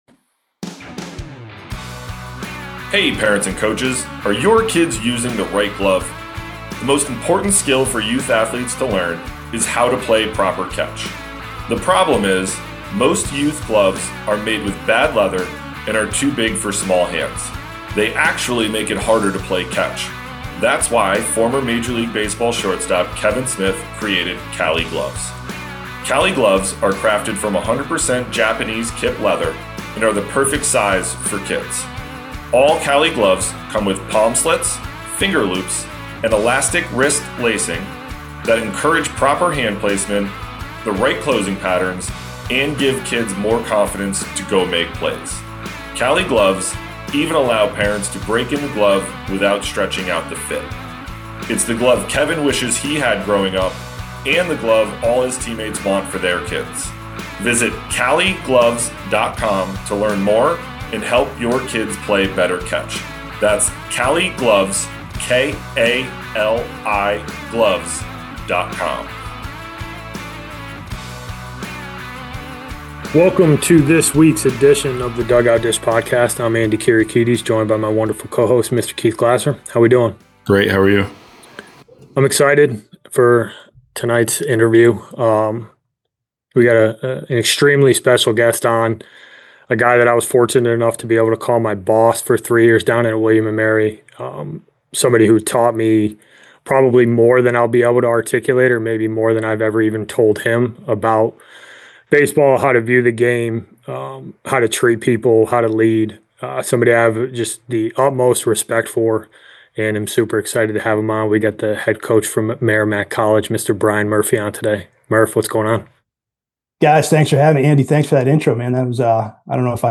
Episode 150: Interview